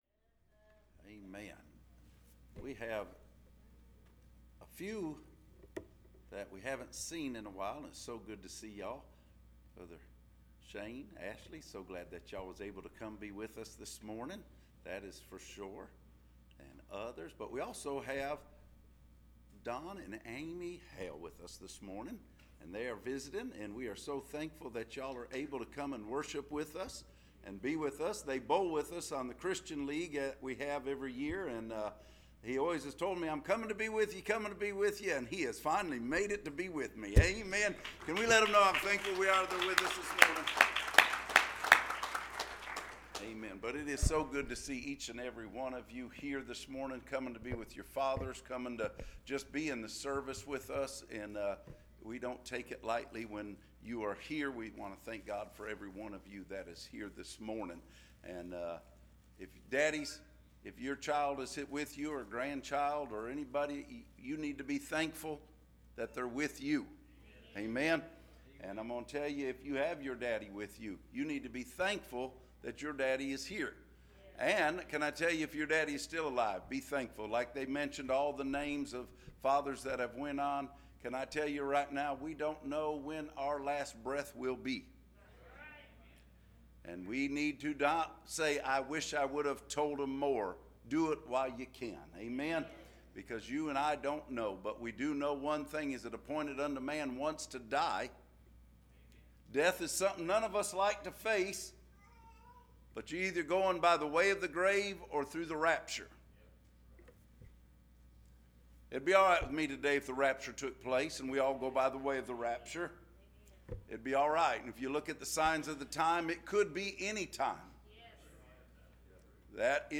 Latest Sermon: Listen Download